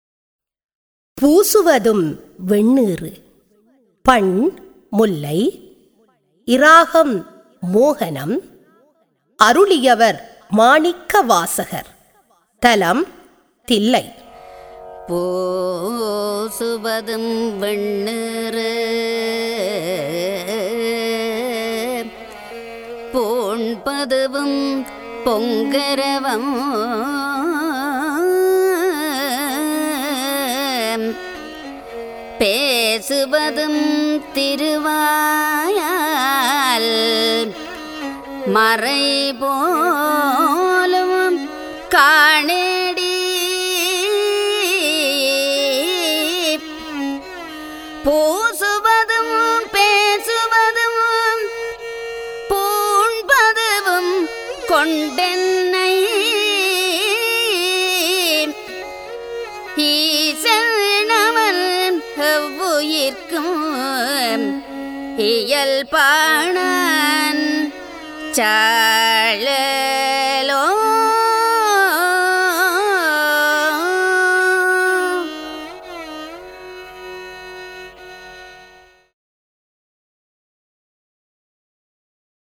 தரம் 6 இல் கல்வி பயிலும் சைவநெறிப் பாடத்தை கற்கும் மணவர்களின் நன்மை கருதி அவர்கள் தேவாரங்களை இலகுவாக மனனம் செய்யும் நோக்கில் இசைவடிவாக்கம் செய்யப்பட்ட தேவாரப்பாடல்கள் இங்கே பதிவிடபட்டுள்ளன.